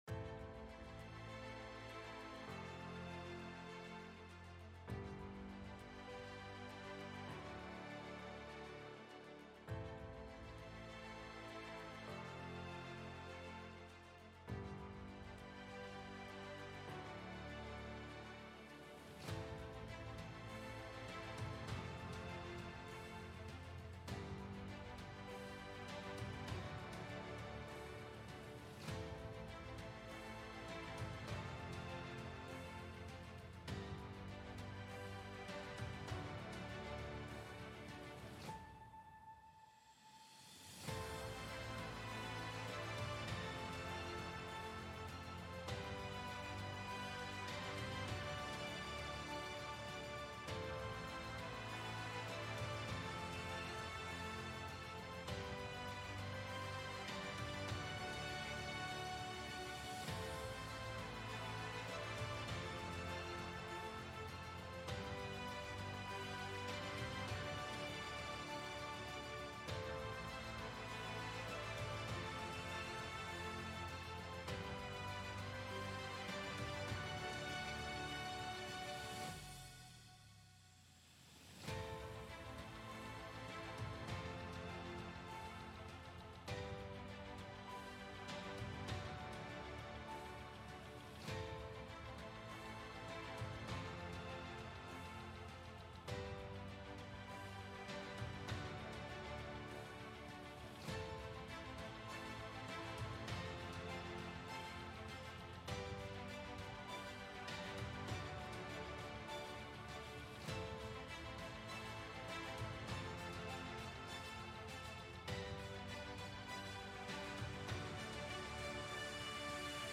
Hintergrundmusik.mp3